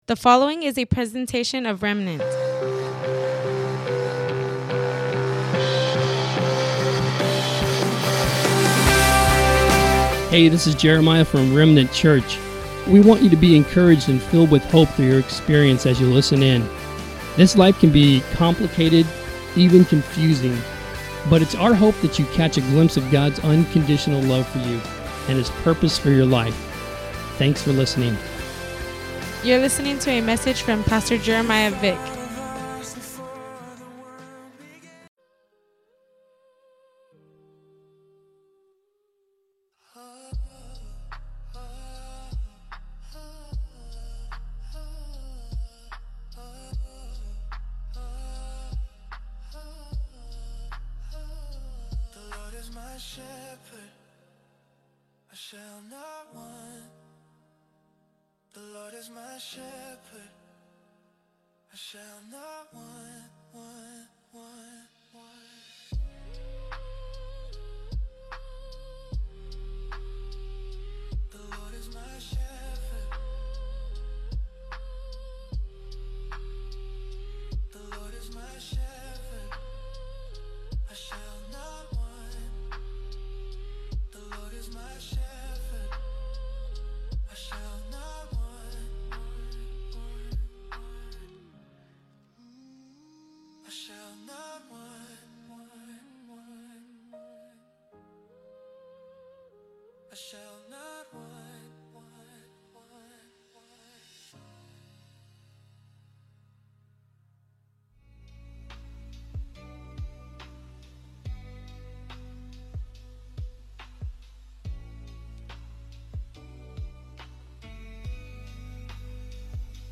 Welcome to the livestream of our Good Friday Service at Remnant Church in Imperial Valley, CA.